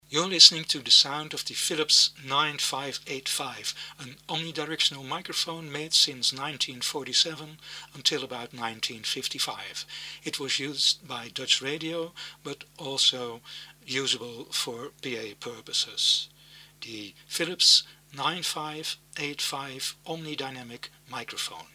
Philips 9585 sound UK.mp3